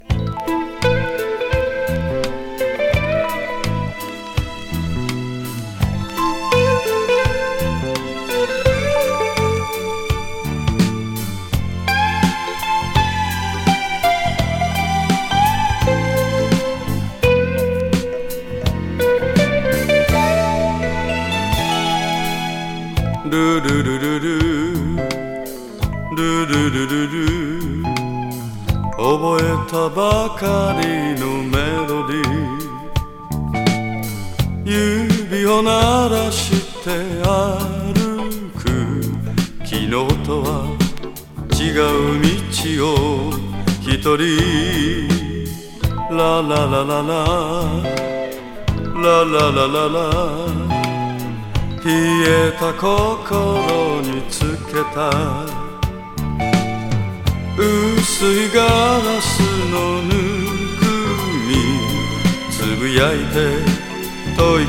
メロウ・ムーディー歌謡